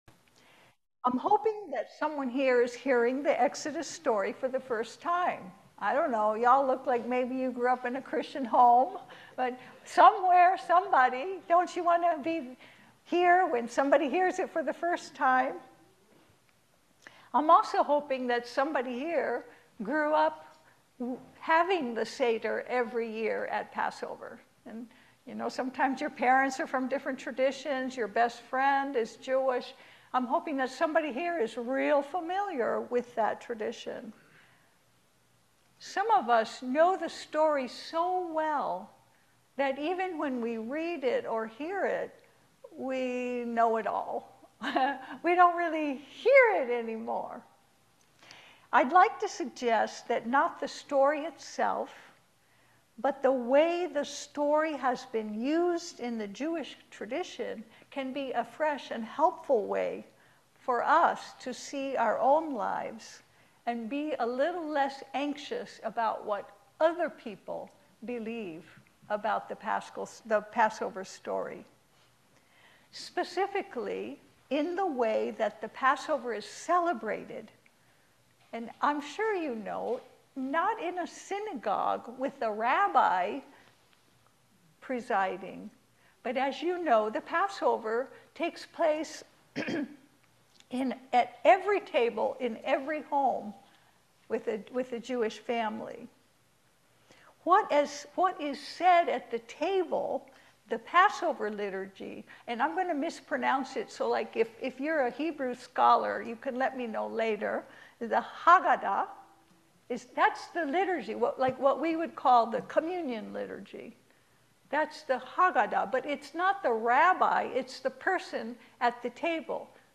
1-14 Service Type: Sunday Service How wide a range of thought